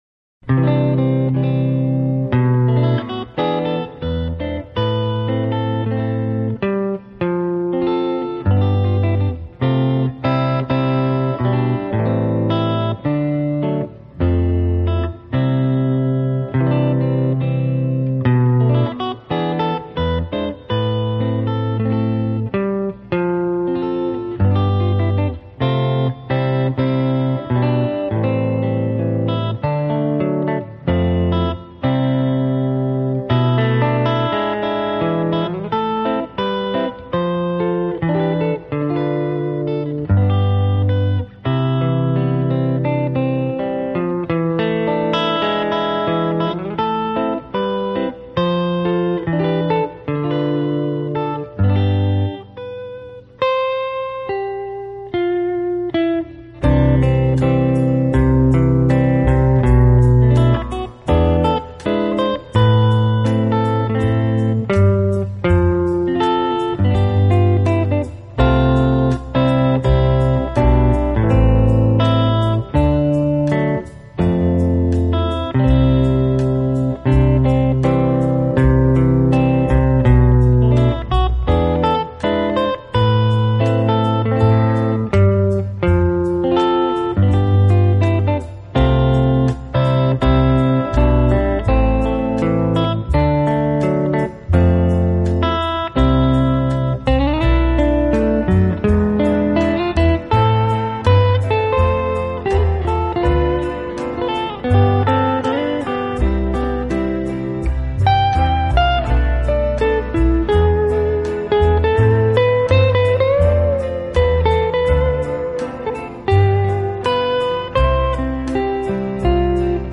【爵士吉他】